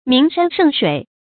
名山勝水 注音： ㄇㄧㄥˊ ㄕㄢ ㄕㄥˋ ㄕㄨㄟˇ 讀音讀法： 意思解釋： 見「名山勝川」。